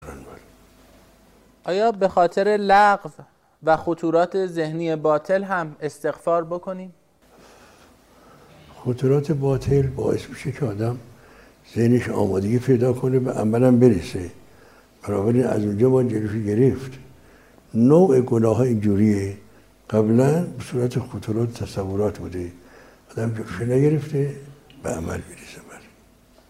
درس اخلاق | آیا به خاطر لغزش‌ها و خطورات ذهنی نیز باید استغفار کرد؟